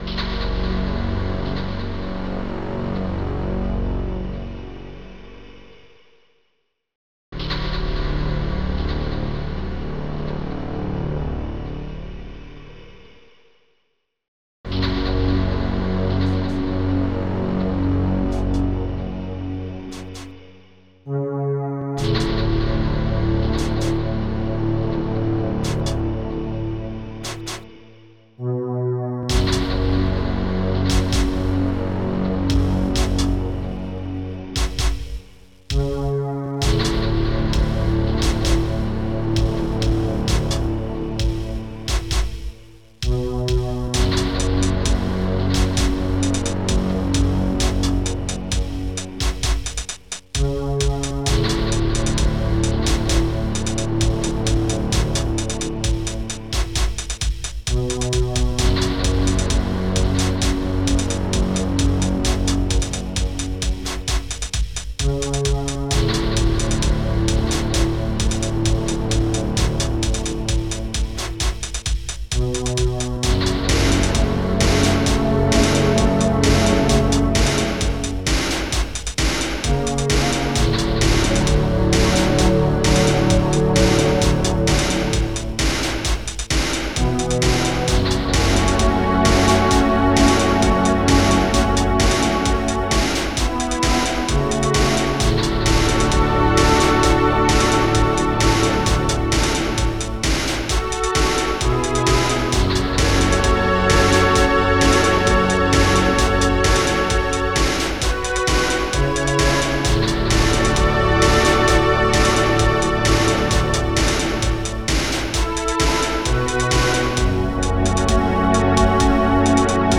Low hum (AC)
PipeHat..Beat